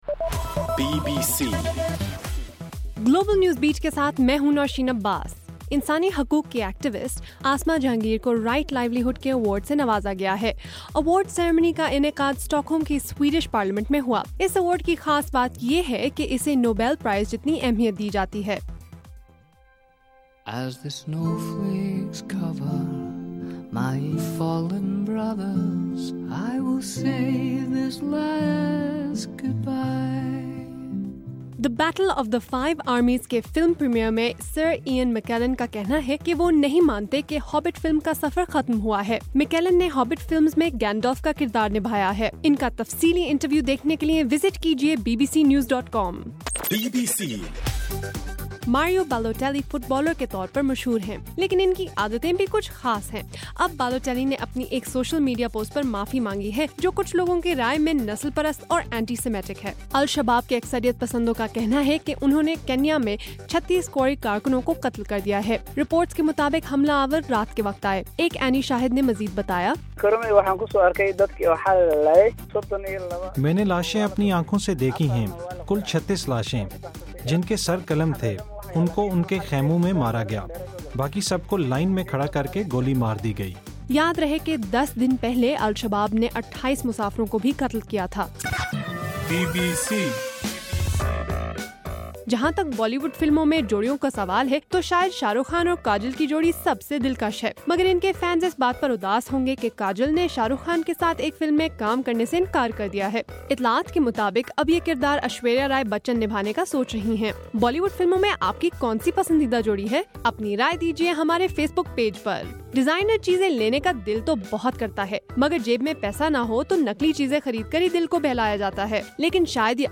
دسمبر 2: رات 8 بجے کا گلوبل نیوز بیٹ بُلیٹن